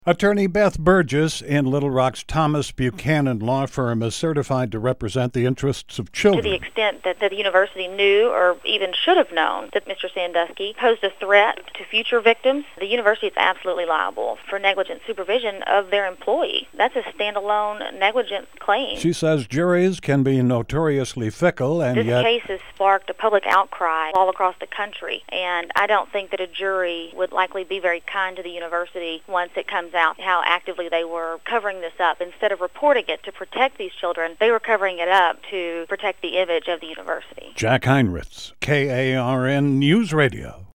Radio Comments.